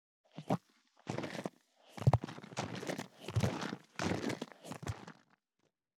342水の音,ジュースを振る,シャカシャカ,カシャカシャ,チャプチャプ,ポチャポチャ,シャバシャバ,チャプン,ドボドボ,
ペットボトル